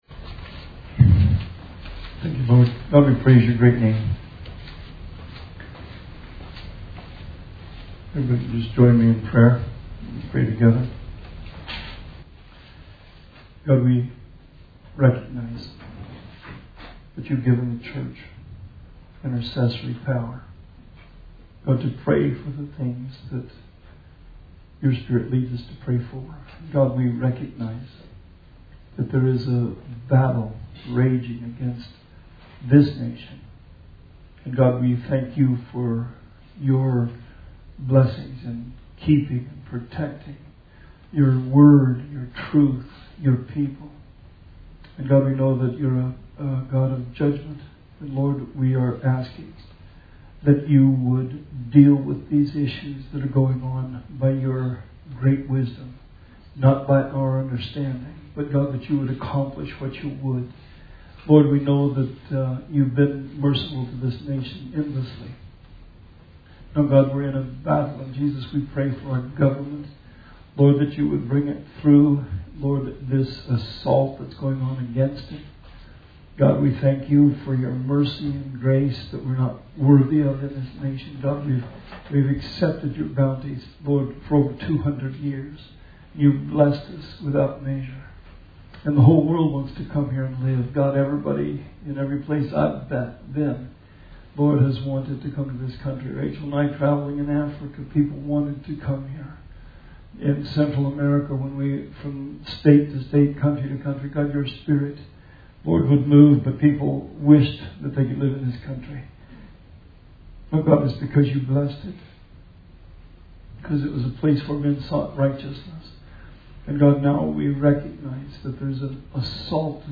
Bible Study 4/22/20